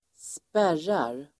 Uttal: [²sp'är:ar]